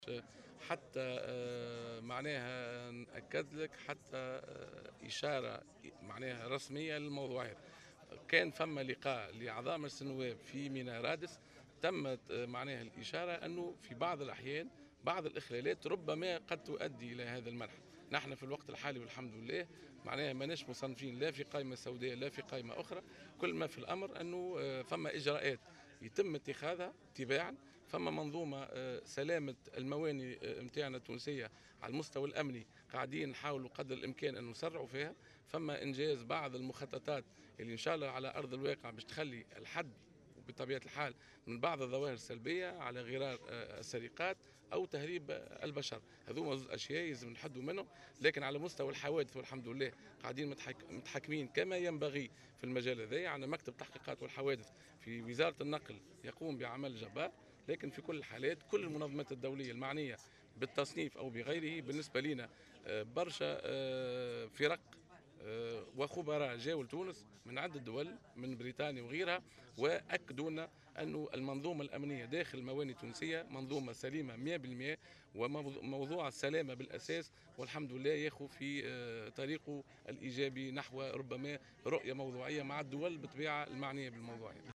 وأضاف في تصريح اليوم على هامش ندوة دولية حول الأمن والسلامة في قطاع النقل البري للأشخاص" بمدينة القيروان أن تونس لم تتلق أي إشارة رسمية من المنظمات المعنية.